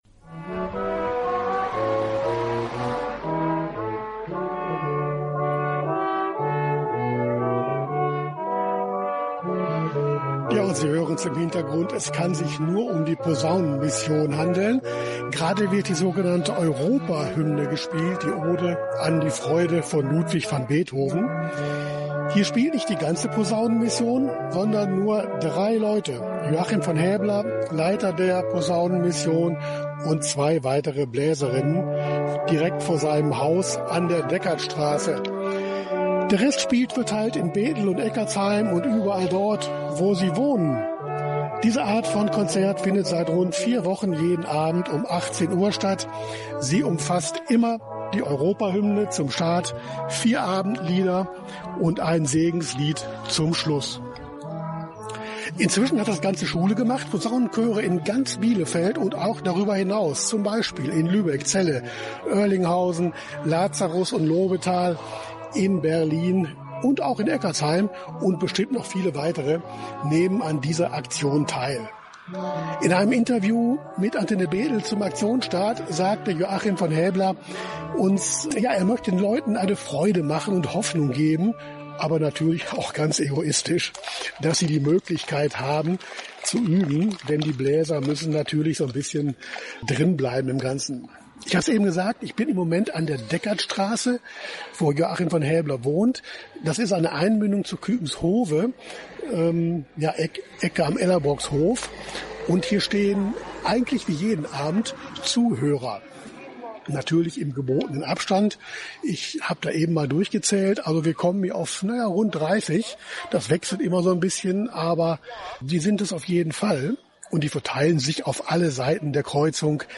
Da in dem Interview noch weitere interessante Aspekte zu hören sind, liefern wir es gleich im Anschluss an die Live-Reportage.
Live-Schalte-Posaunenmission-und-Interview.mp3